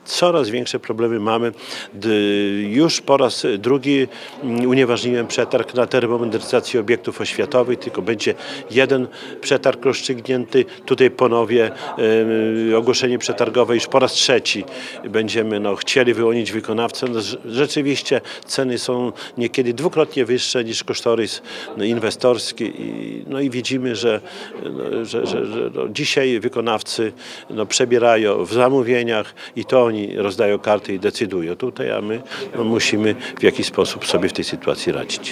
Czesław Renkiewicz, prezydent Suwałk nie krył, że problem zaistniał i wyjaśniał, że nie wszystko zależy od samorządu. Na rynku brakuje firm chętnych do pracy.
Czesław-Renkiewicz-o-remontach-szkół-02.mp3